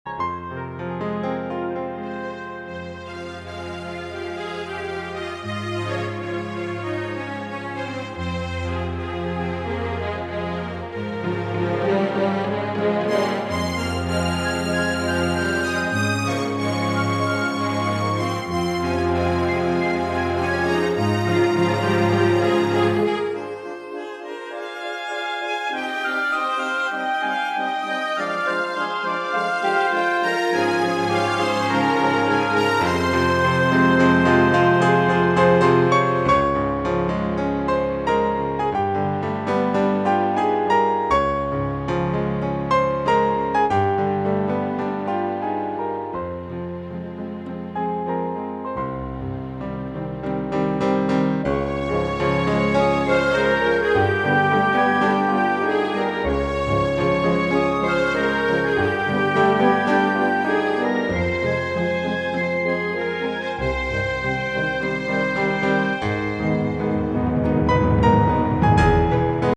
Additional pieces using synthesized orchestra.
Synthesized orchestra